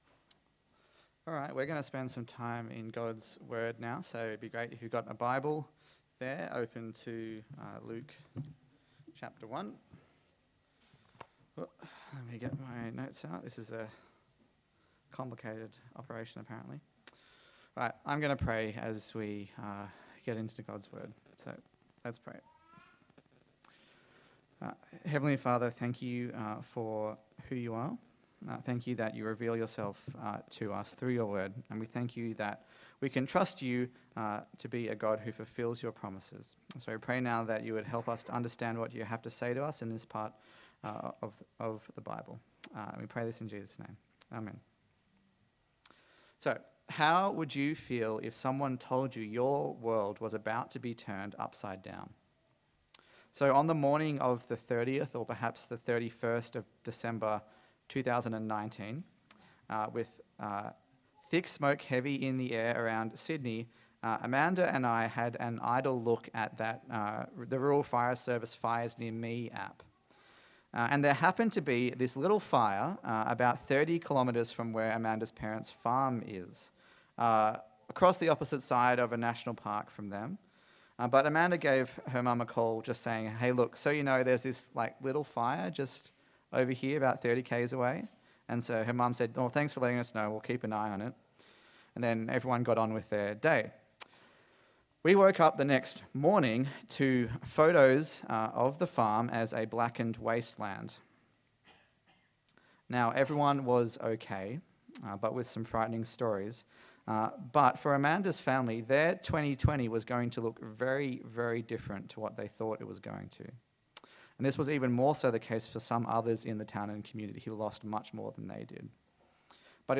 A sermon in the series on the book of Luke
Service Type: Sunday Morning